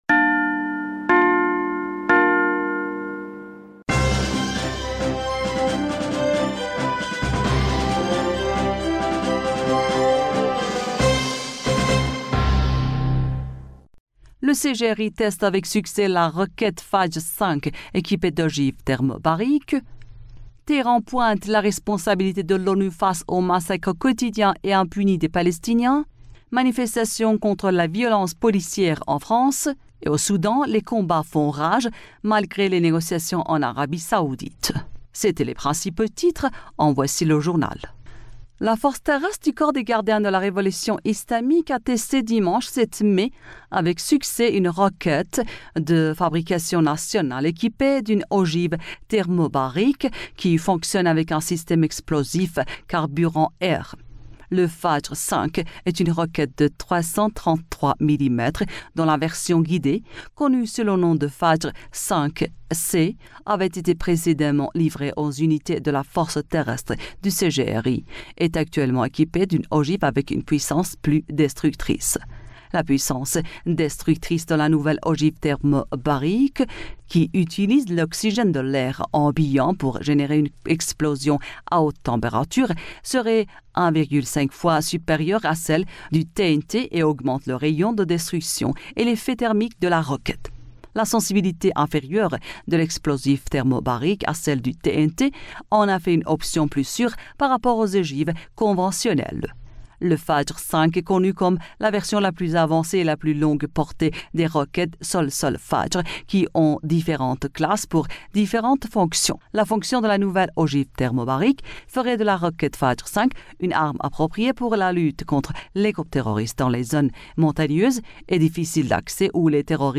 Bulletin d'information du 08 Mai 2023